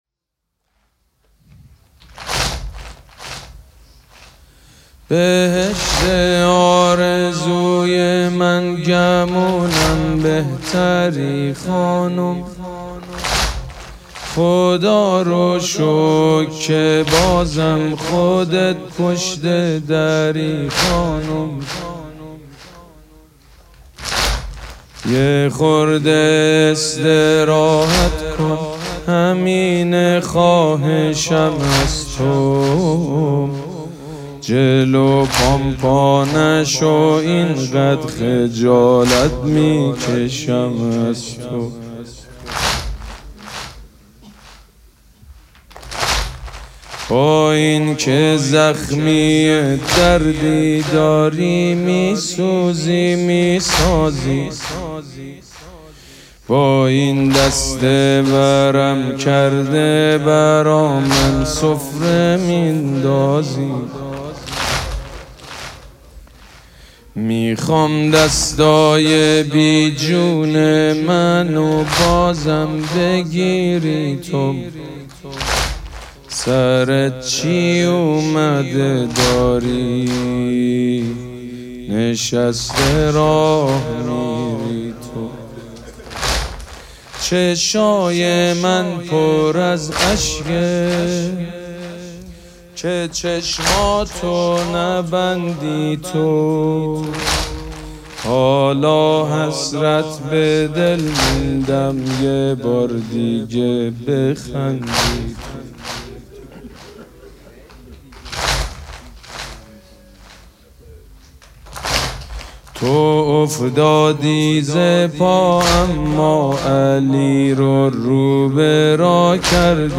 حاج سيد مجید بنی فاطمه
شب چهارم فاطمیه 1403